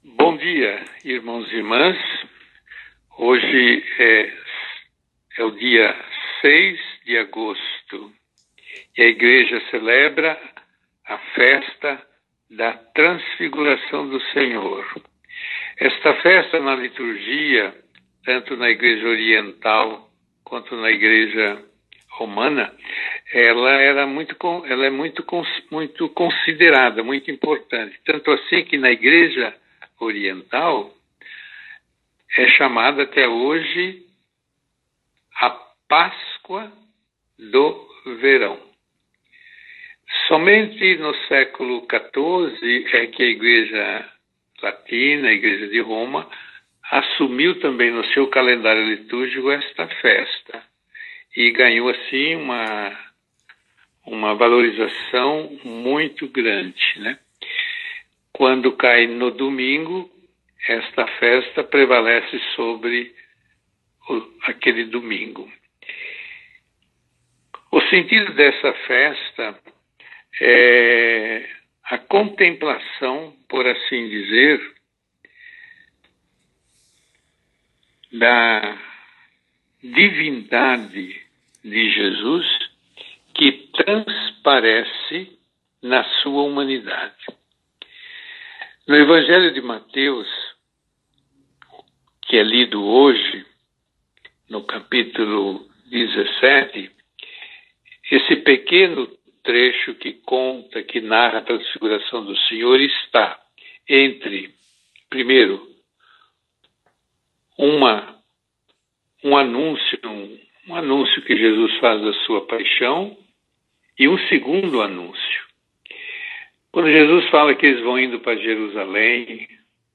Finalizando, um lindo canto liturgico especialmente dirigido à ocasião.